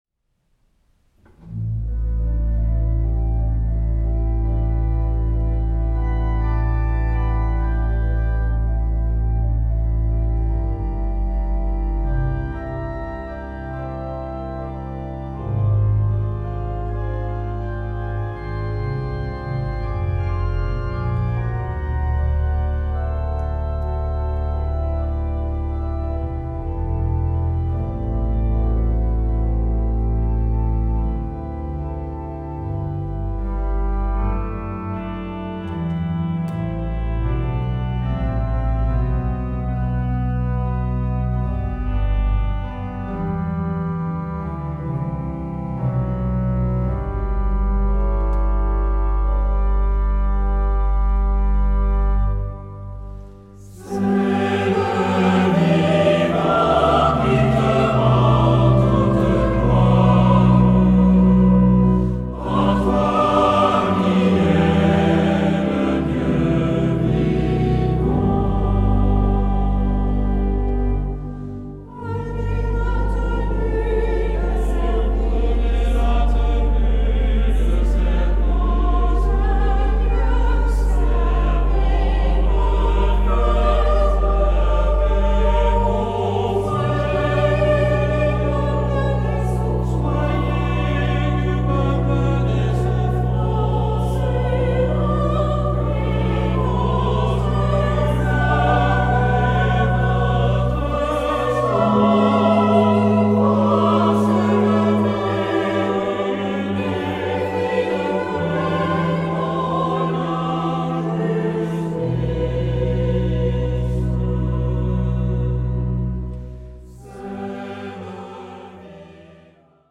Epoque: 20th century
Genre-Style-Form: Hymn (sacred) ; Sacred
Type of Choir: SATB  (4 mixed voices )
Instruments: Organ (1)
Tonality: A major